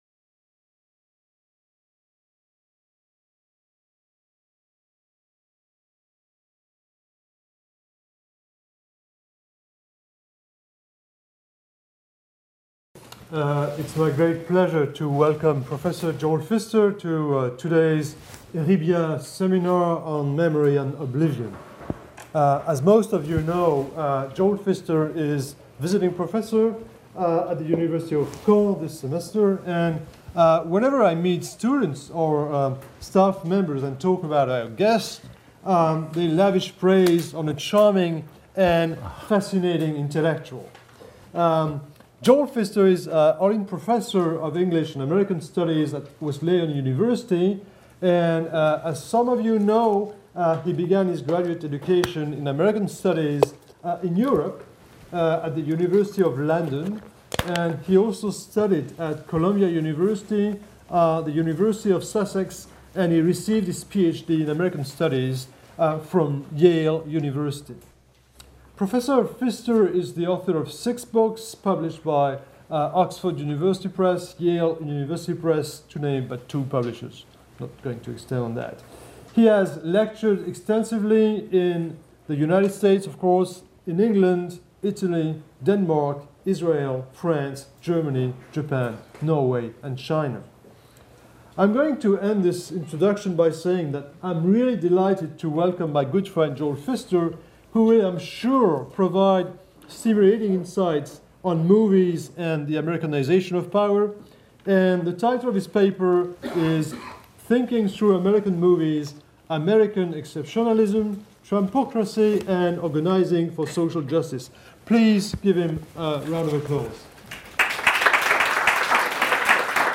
Séminaires ERIBIA 2017-2018